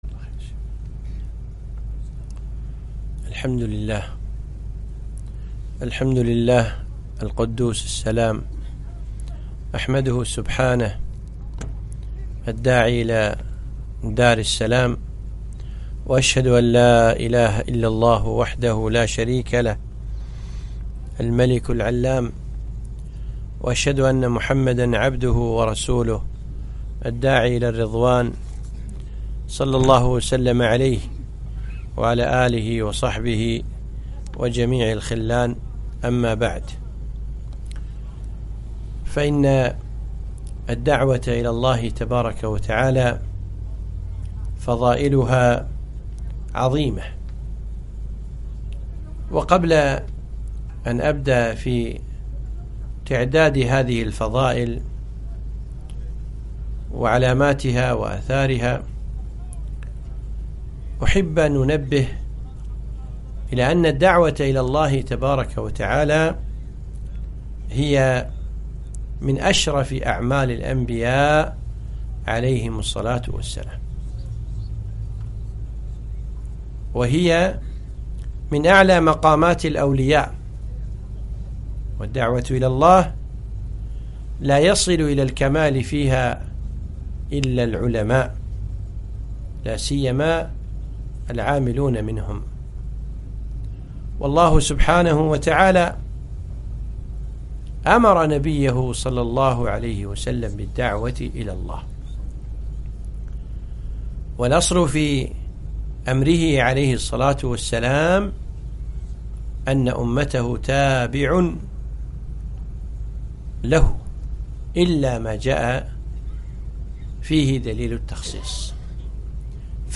محاضرة - فضائل الدعوة إلى الله